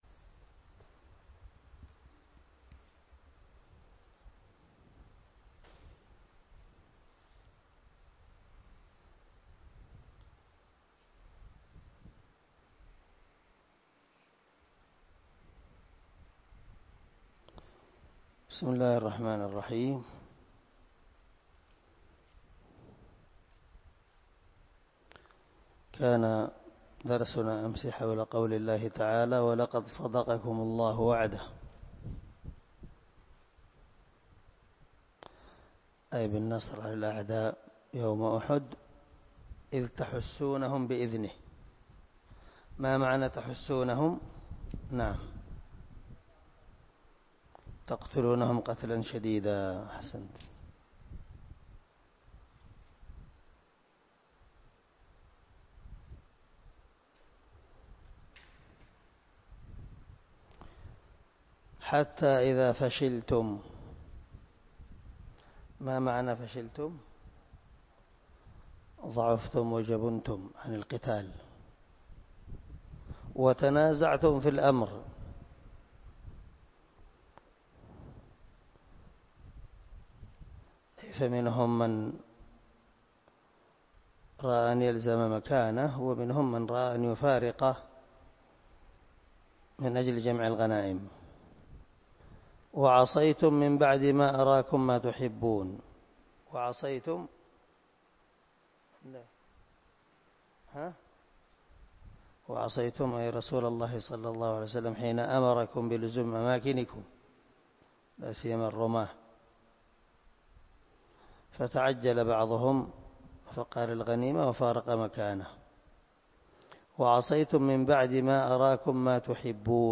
207الدرس 52 تفسير آية ( 153 – 154 ) من سورة آل عمران من تفسير القران الكريم مع قراءة لتفسير السعدي